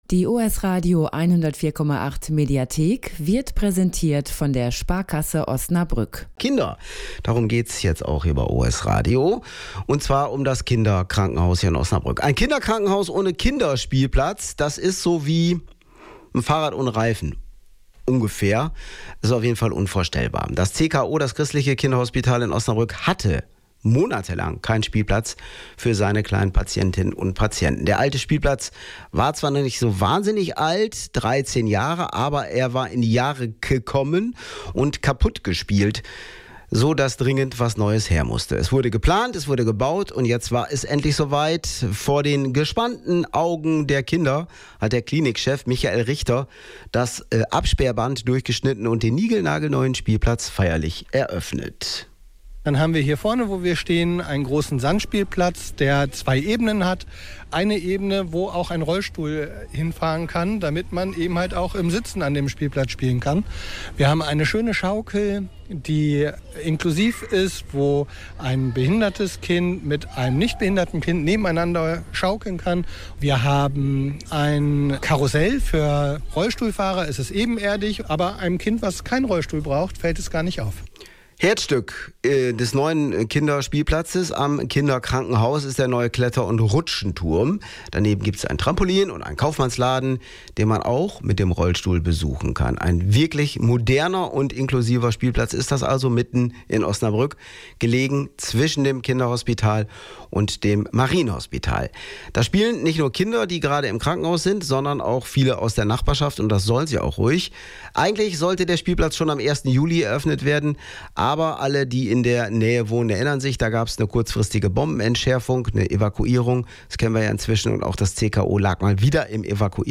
Radio Beitrag von OS-Radio